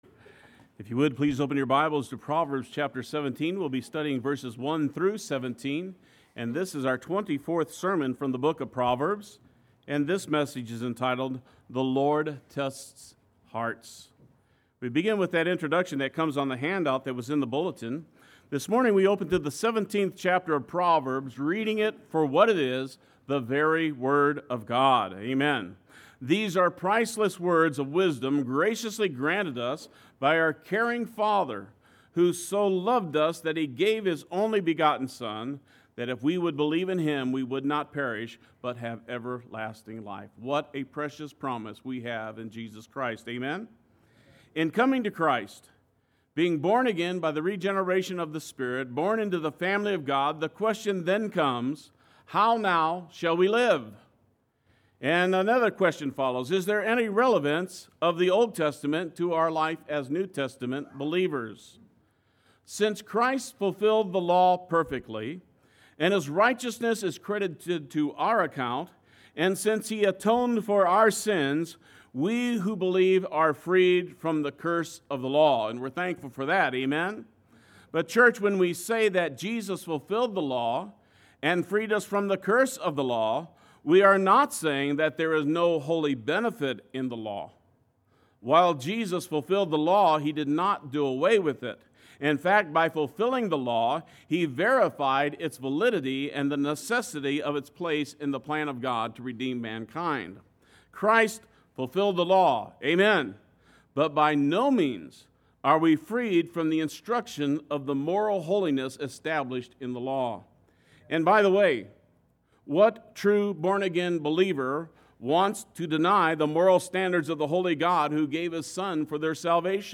Play Sermon Get HCF Teaching Automatically.
The Lord Tests Hearts Sunday Worship